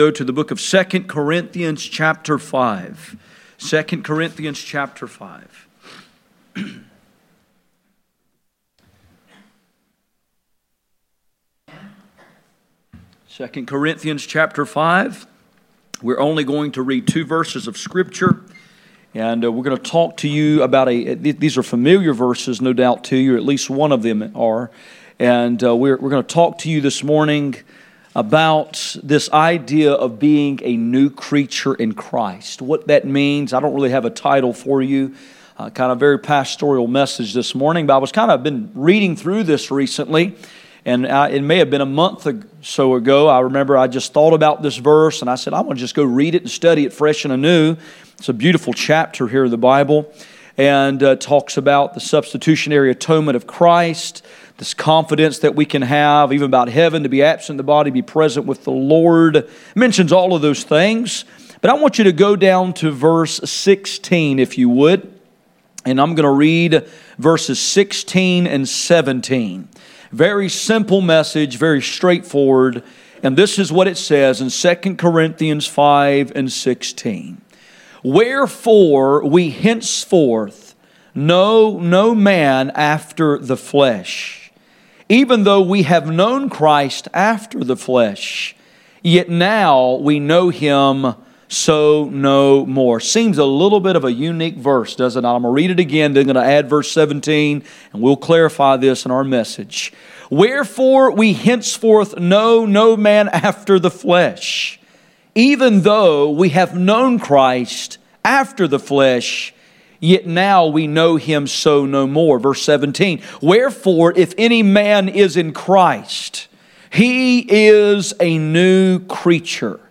None Passage: 2 Corinthians 5:16-17 Service Type: Sunday Morning %todo_render% « The cure for ignorance